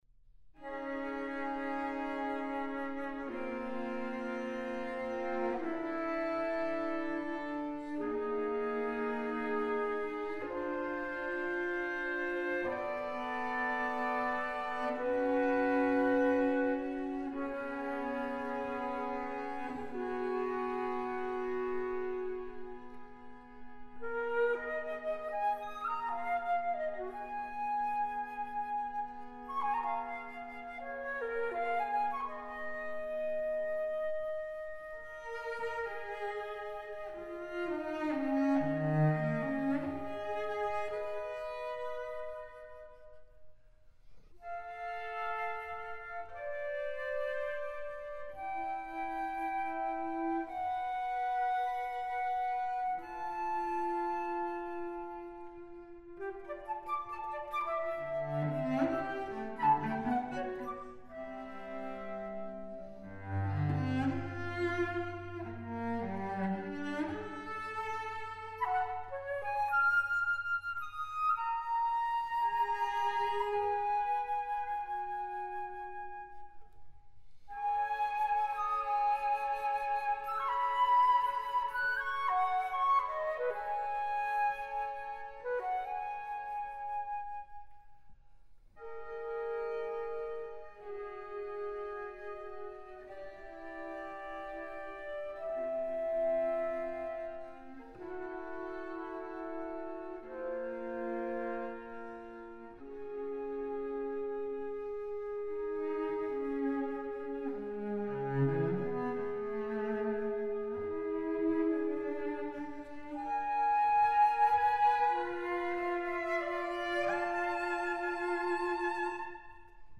Instrumentation: Flute and cello